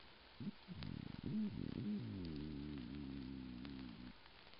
Sounds Made by Epinephelus guttatus
Type of sound produced courtship associated sounds and territorial displays of males
Sound production organ swim bladder
Sound mechanism vibration by contraction of associated muscles (probably similar as in Epinephelusstriatus)
Behavioural context courtship displays between males and females and territorial displays by males recorded in situ during the spawning aggregation in western Puerto Rico